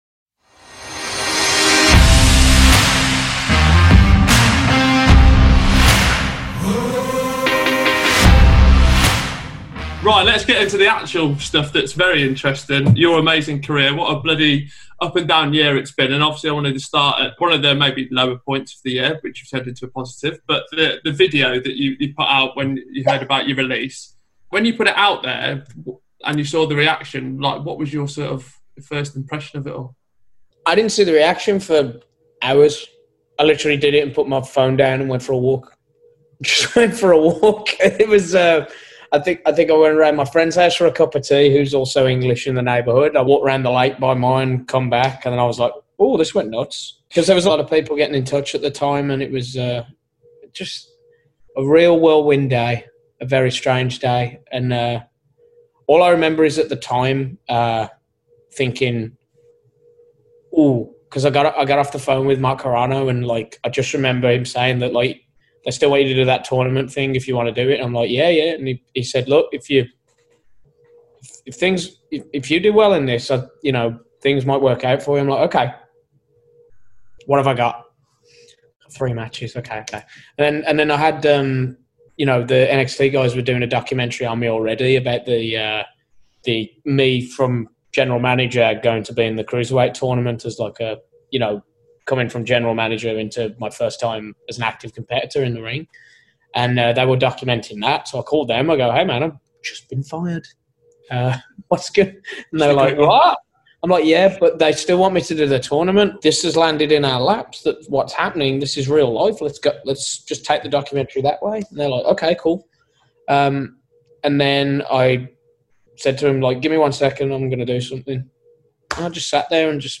EXCLUSIVE INTERVIEW: Drake Maverick on his future in wrestling